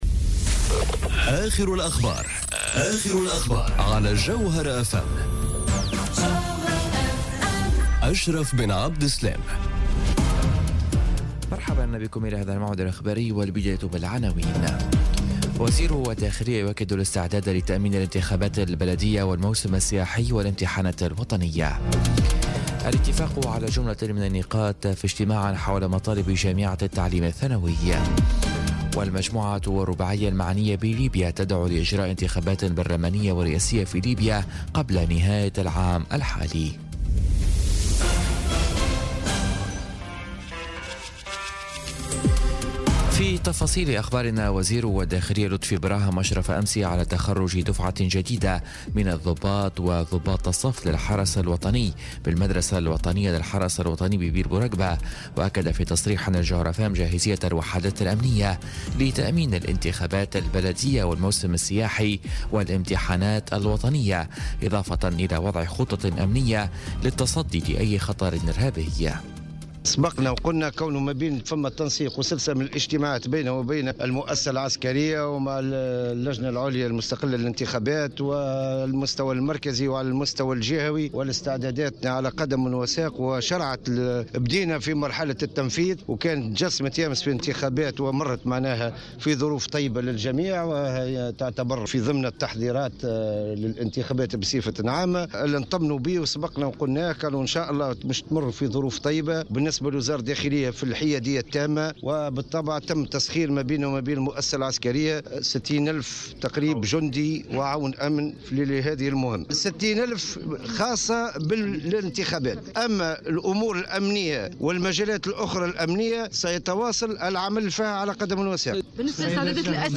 نشرة أخبار منتصف الليل ليوم الثلاثاء 1 ماي 2018